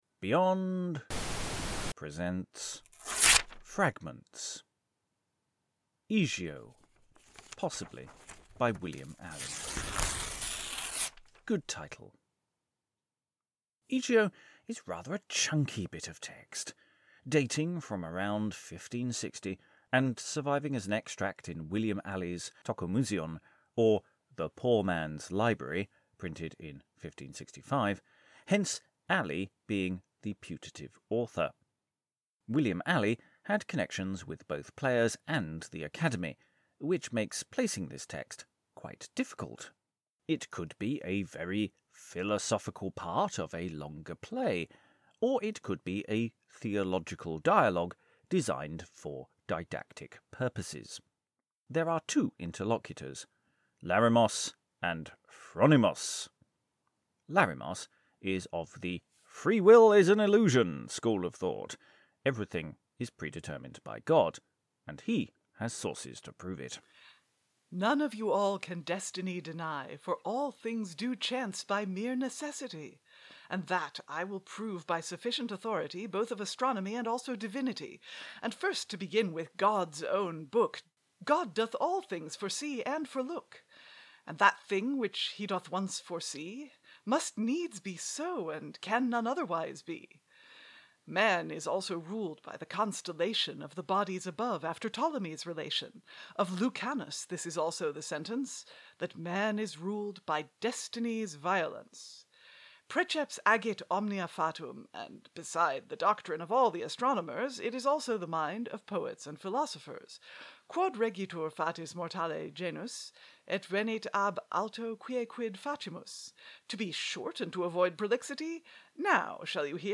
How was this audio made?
Another fragment is given a bit of love - this is based on a recording we made from a live stream, but given a tidy up and the usual Fragments treatment.